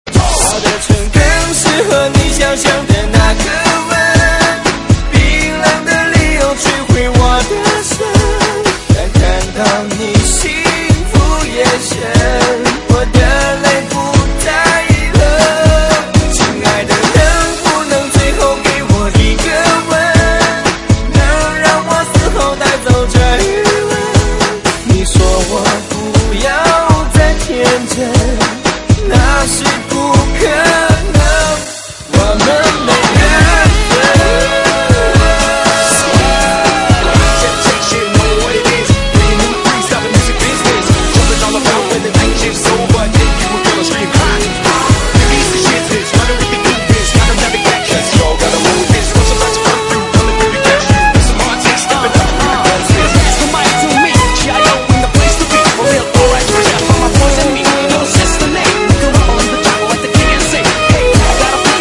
DJ铃声 大小
DJ舞曲